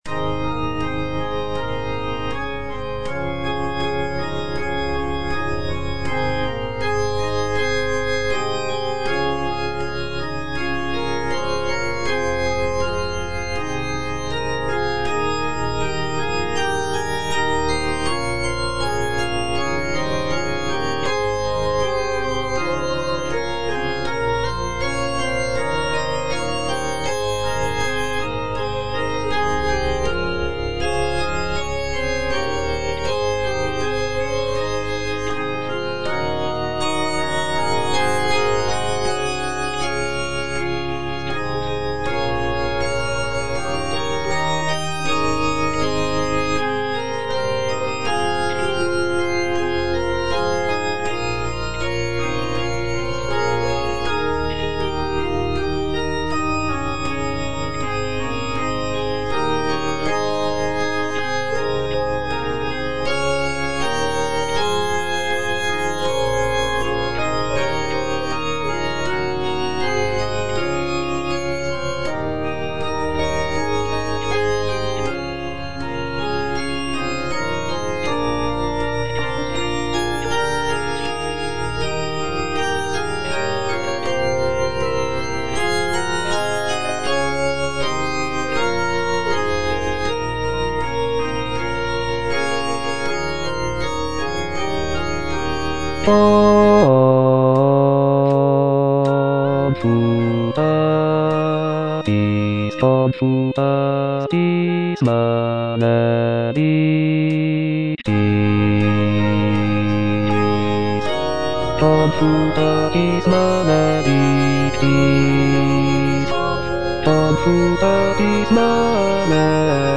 Bass (Voice with metronome) Ads stop
is a sacred choral work rooted in his Christian faith.